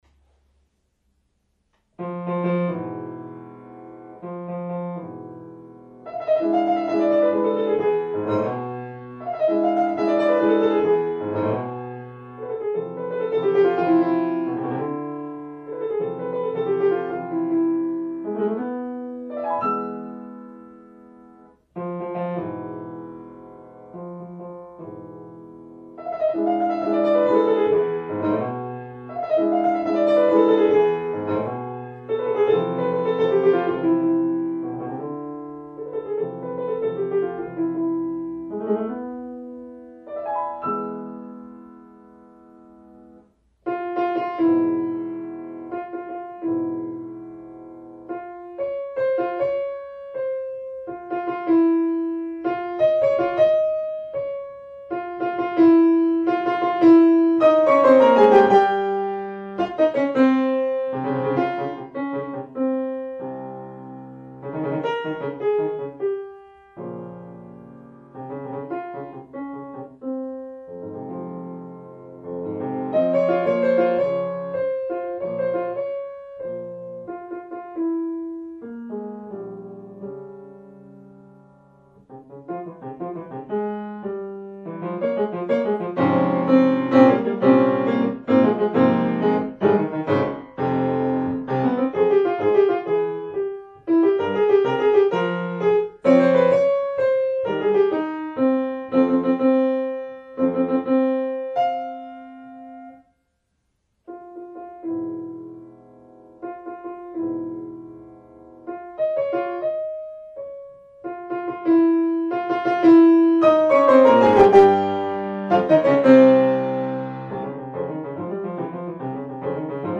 “Danza Homenaje a Antonio Machado” (piano)